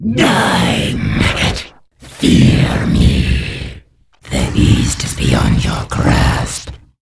Ob es die Sprecher sind, die innerhalb der unglaublichen Cinematists oder im Spiel selbst (
Andariel, Schmied) agieren, ob es die Geräusche jedes einzelnen Gegenstandes sind, der zu Boden fällt oder den man im Inventar anklickt, oder die Musik, die man auch durchaus für sich allein hören kann und nur ganz wenige Ausreißer nach unten hat - auch nach Jahren hat daran kaum ein Spiel kratzen können.